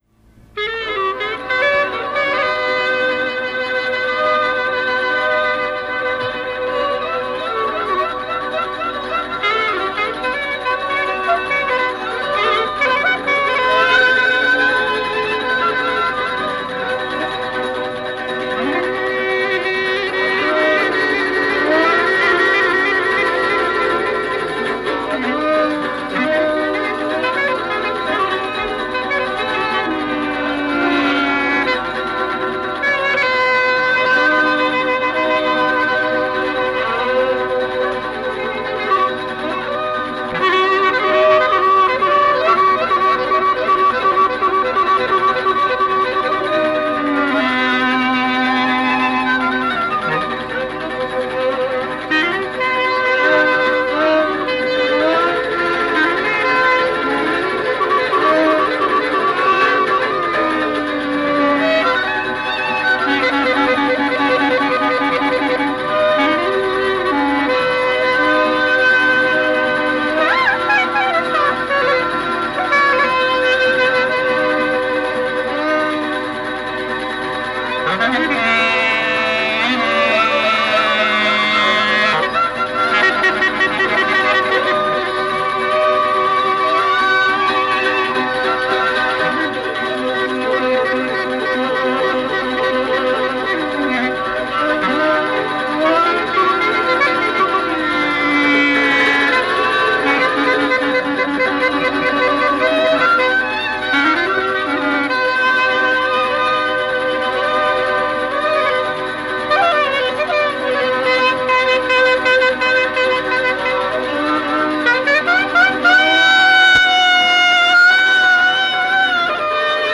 Kjo kaba është riinterpretim i asaj që njihet si “Kabaja e Mediut”, me dy pjesë, kaba e valle.
Siç kemi theksuar për këtë tip kabaje, tipike është motivi vajtimor mbi të cilin zhvillohet pothuajse e gjithë pjesa e saj e parë, dialogu marrje–prerje mes gërnetës dhe violinës, nën ison konstante të llautës e cila në këtë variant nuk është më në Si b si te kabaja që luan Medi Përmeti, por në notën Do.
Ashtu si në kabanë origjinale, kabaja ka një dhimbje të përmbajtur e cila herë pas here shpërthen në kulminacione patetike që lidhen me gjendjen e interpretuesit. Në këtë kaba ndihet një qëndrim më dinamik i gërnetës në raport me paraardhësin.
Pjesa e dytë shfaqet si valle e gëzuar e mjaft dinamike.